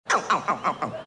PLAY risa flaco
risa-flaco.mp3